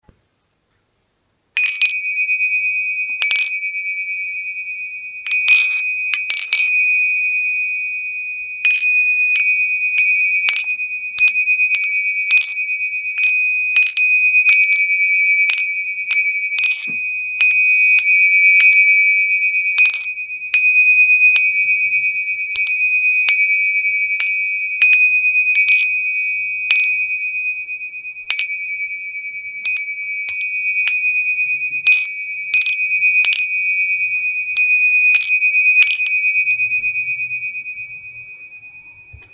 Ting sha chimes/bells 2 - Crystal Master
Tingsha chimes/bells 80mm diam.
Blue Ting sha
blue-tingsha-2.mp3